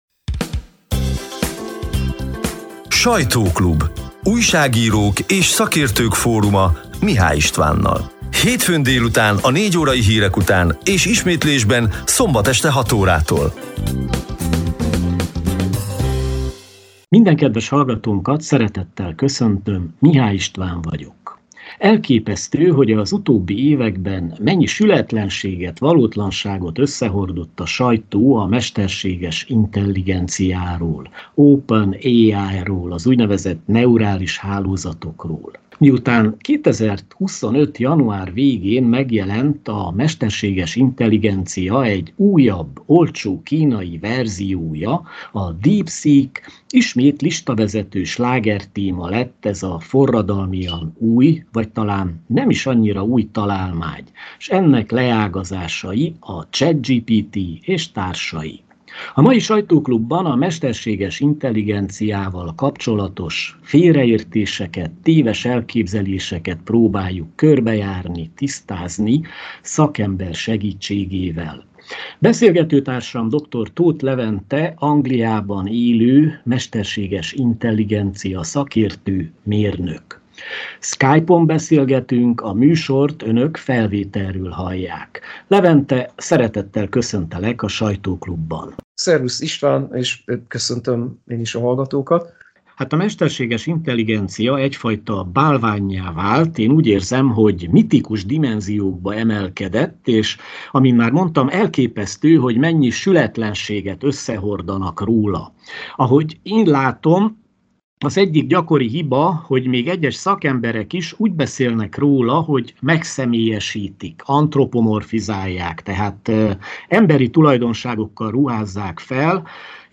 A lejátszóra kattintva az hétfő délutáni 55 perces műsor kissé rövidített változatát hallgathatják meg.